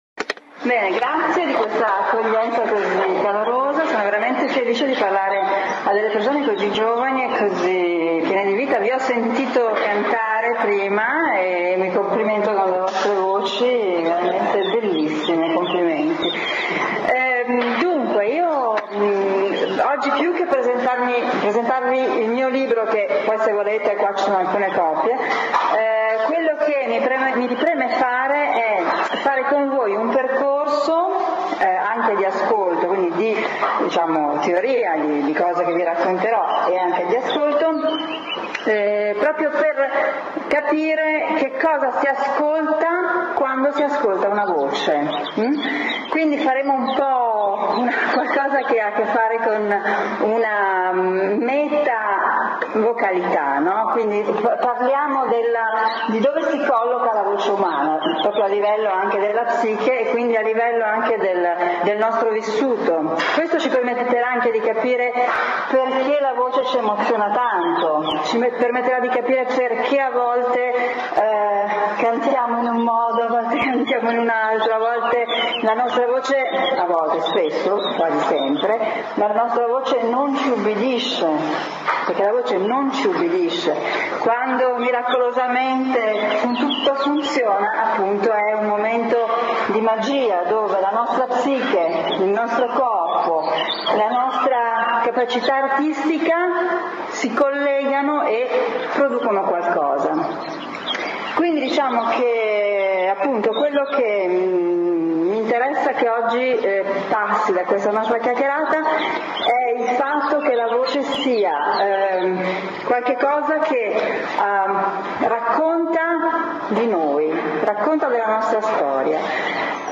ASCOLTA LA LEZIONE SULLA VOCE (59 min)